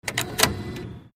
cardinsert.mp3